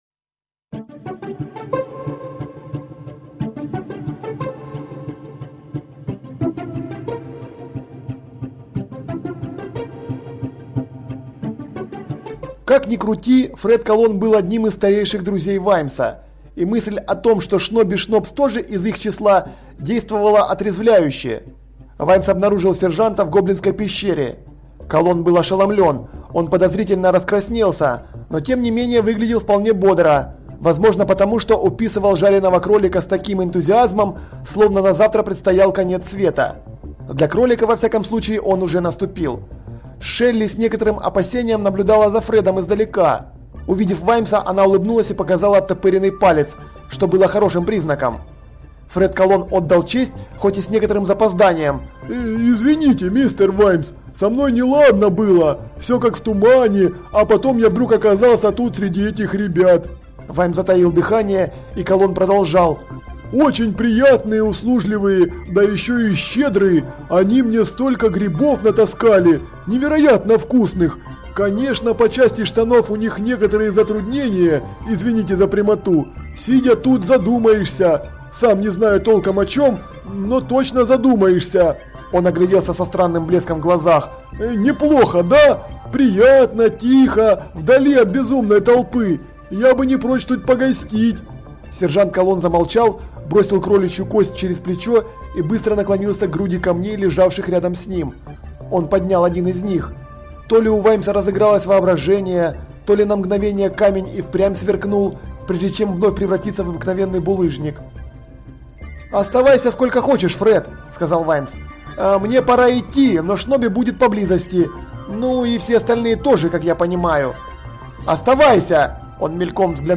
Аудиокнига Дело табак
Качество озвучивания весьма высокое.